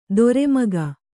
♪ dore maga